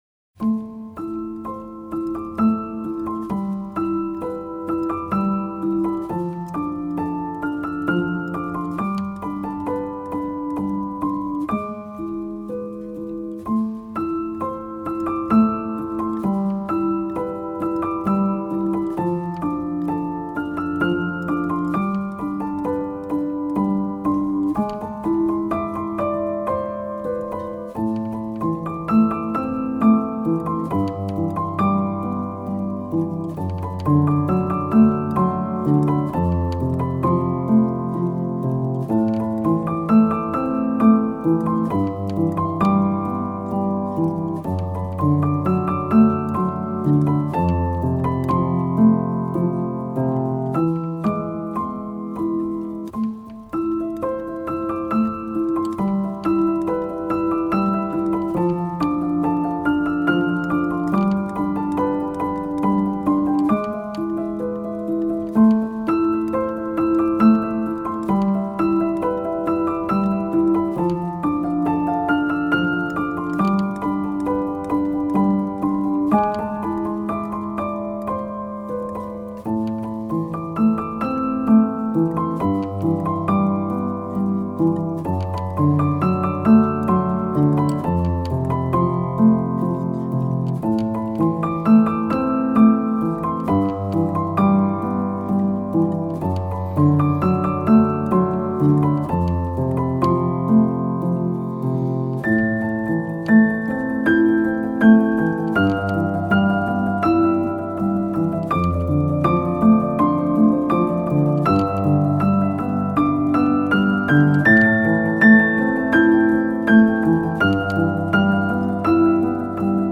آرامش بخش , ابری و بارانی , پیانو , مدرن کلاسیک